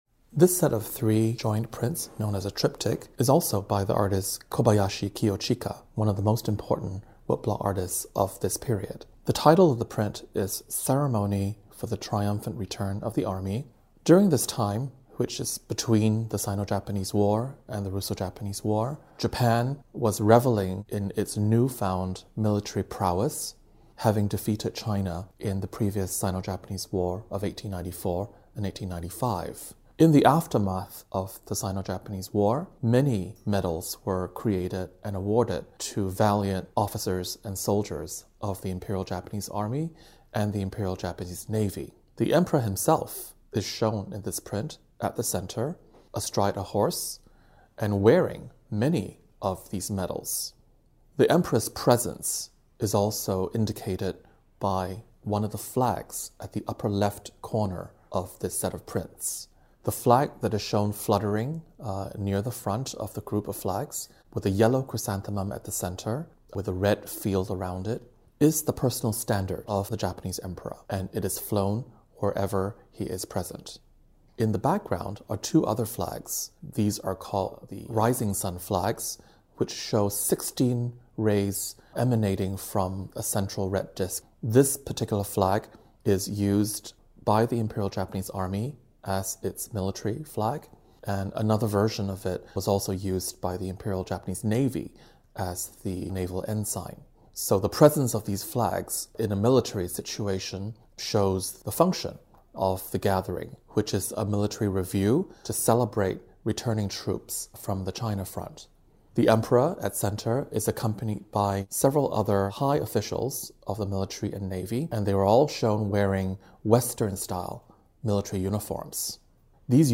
This audio guide features an introduction in both English and Chinese, and expert commentary on 8 works of art from the exhibition.